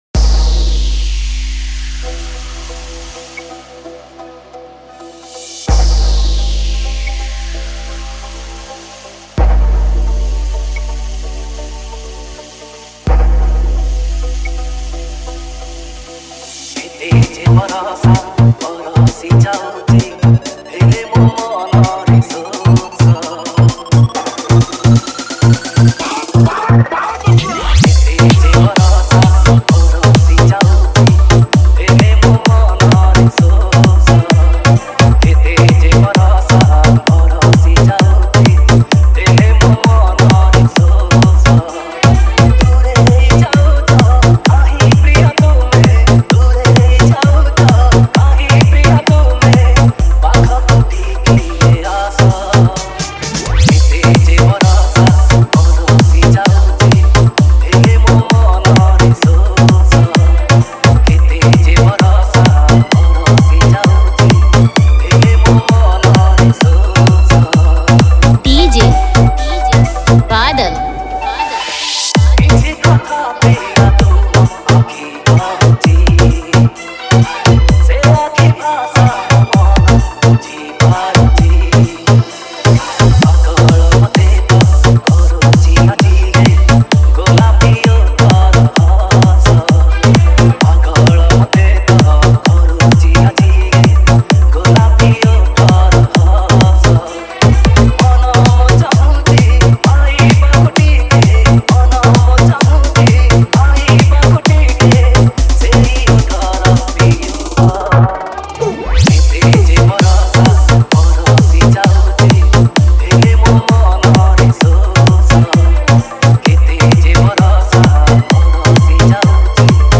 Category:  New Odia Dj Song 2020